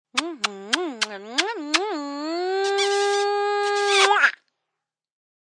Звуки воздушного поцелуя
Женщина средних лет рассылает множество воздушных поцелуев одновременно